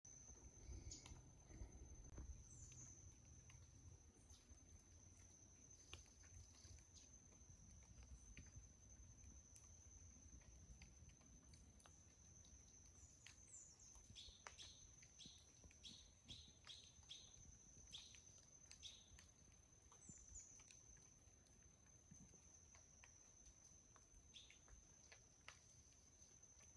Insects and raindrops.
LaFortunaForestSounts06.mp3